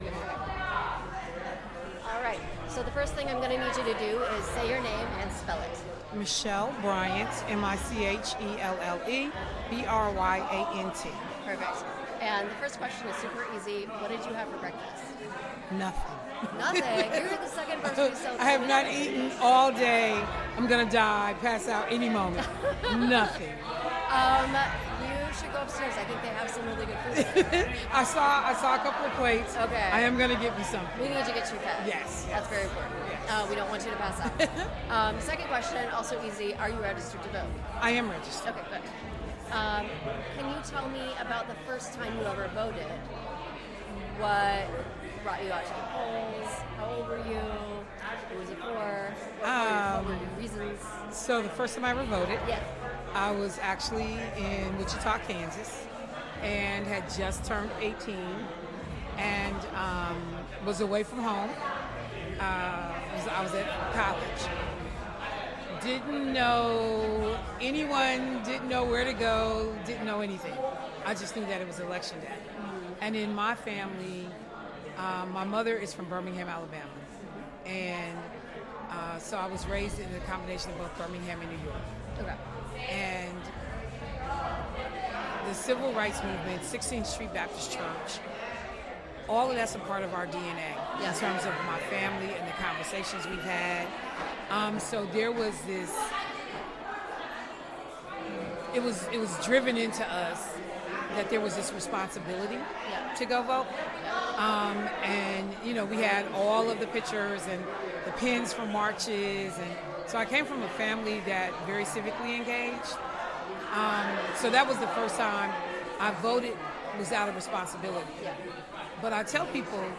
Location Turner Hall